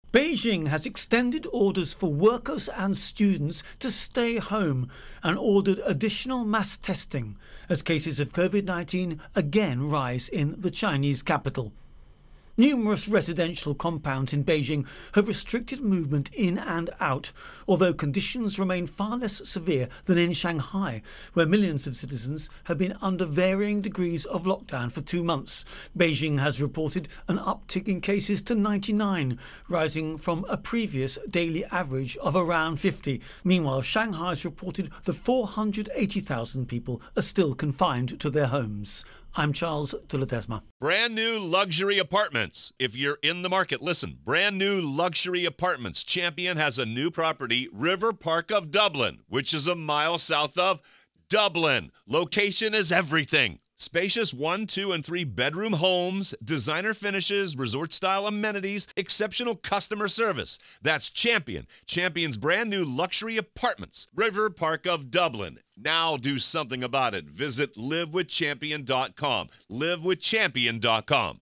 Virus Outbreak China Intro and Voicer